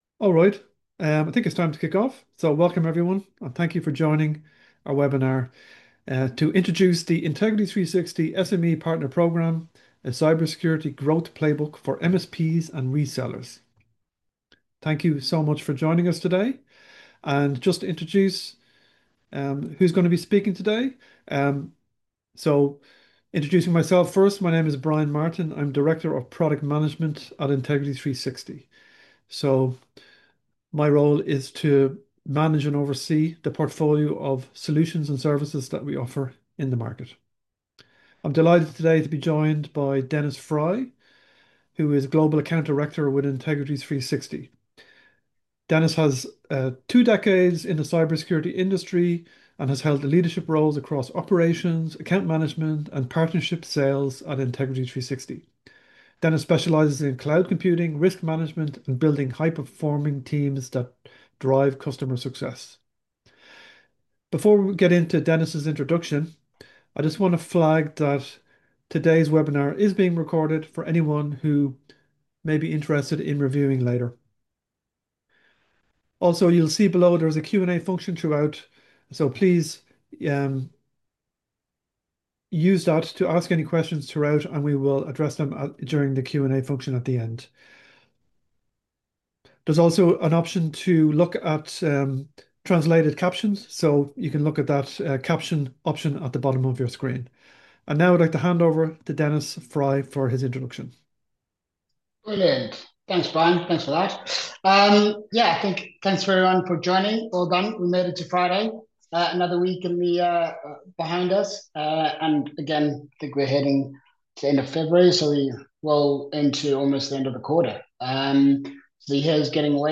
The Integrity360 SME partner programme – A cybersecurity growth playbook for MSPs & resellers This is the recording of our live webinar held on February 27th, 2026 Listen to audio View on demand recording